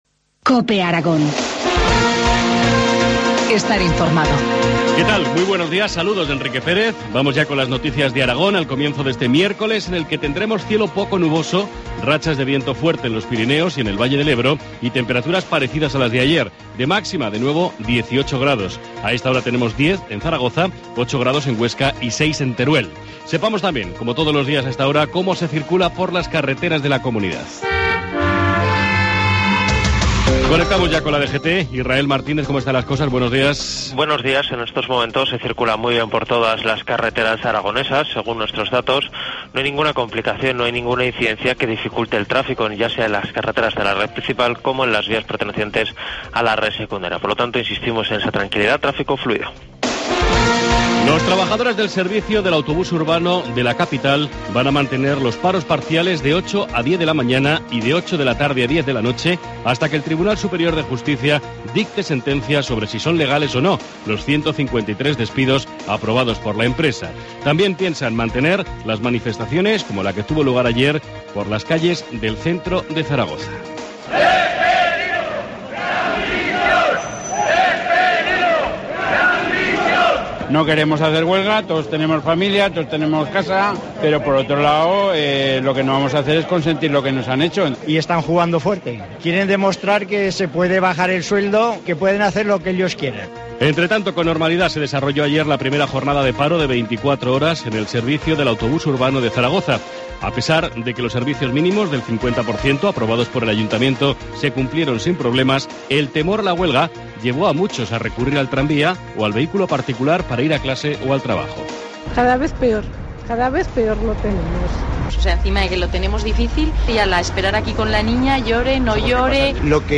Informativo matinal, miércoles 30 de octubre, 7.25 horas